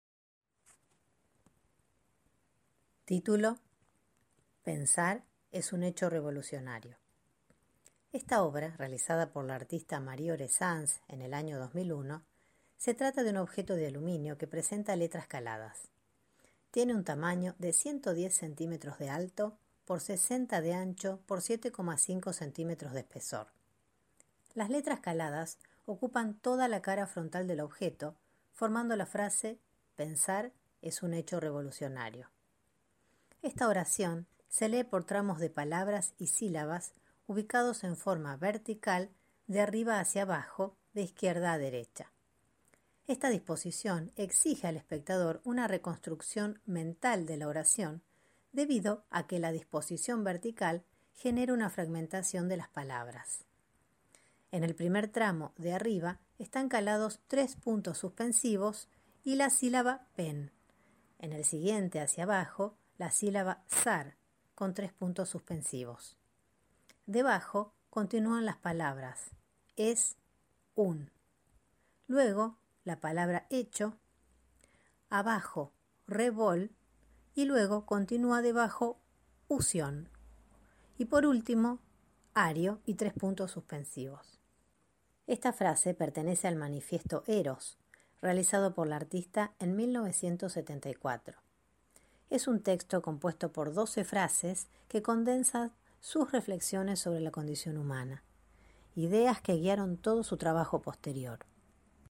Audiodescripción de la obra Pensar es un hecho revolucionario de Marie Orezanz
orezanz_marie_pensar_es_un_hecho_revolucionario_audiodescripcion.mp3